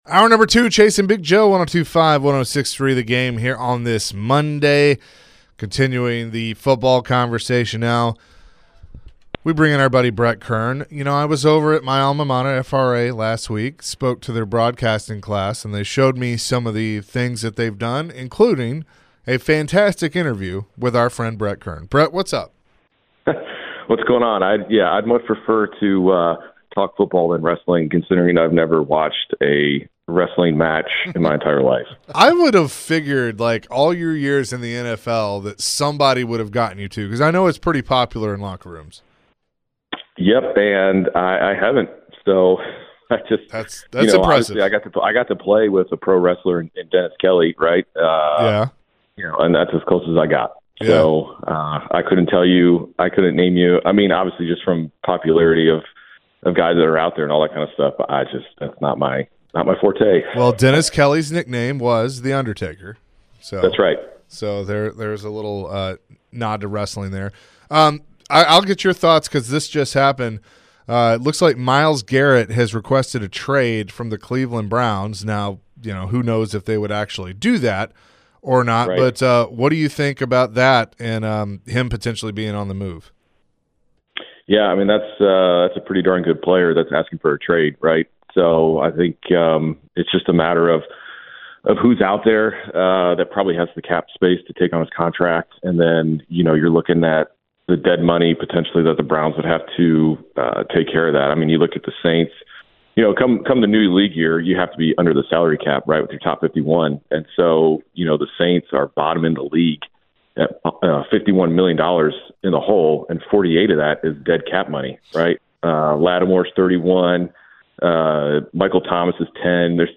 former Titans Punter Brett Kern joined the show and shared his thoughts on the recent news about Myles Garrett requesting a trade